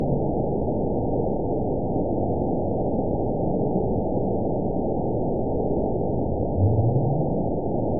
event 919832 date 01/25/24 time 15:48:49 GMT (3 months ago) score 9.38 location TSS-AB06 detected by nrw target species NRW annotations +NRW Spectrogram: Frequency (kHz) vs. Time (s) audio not available .wav